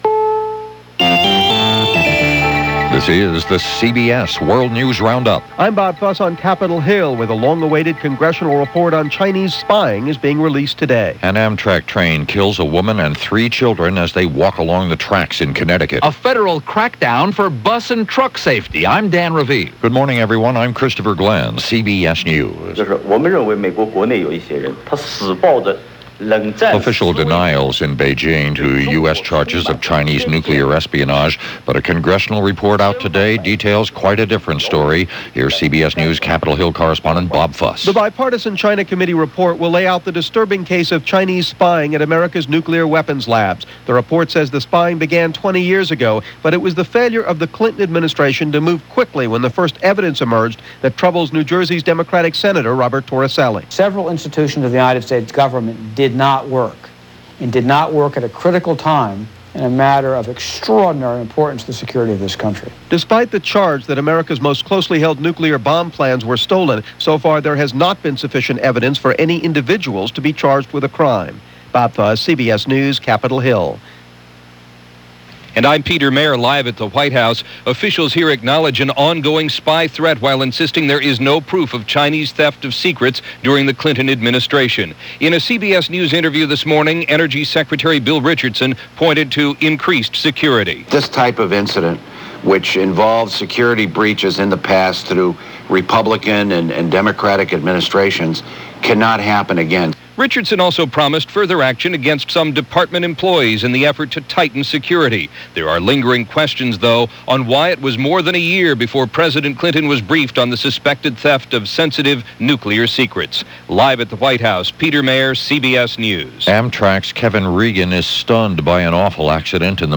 May 25, 1993 – CBS World News Roundup – Gordon Skene Sound Collection –